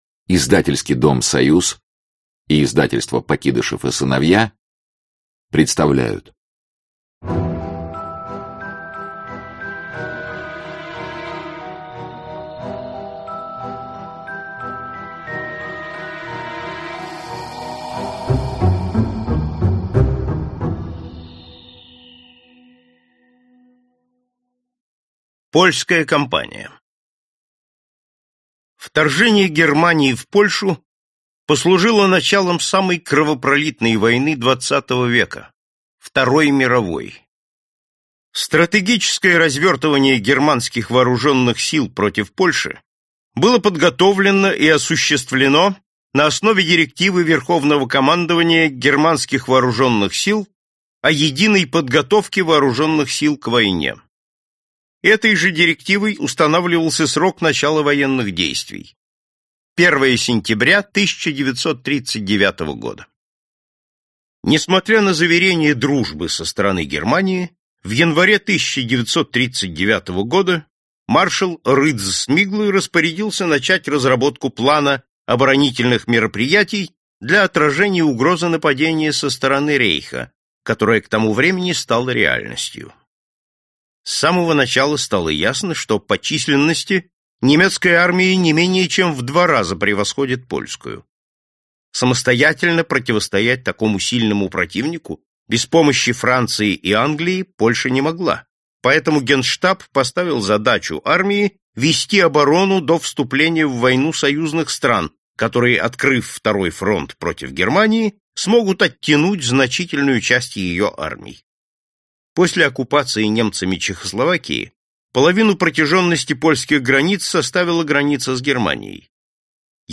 Аудиокнига Великие сражения Второй мировой войны | Библиотека аудиокниг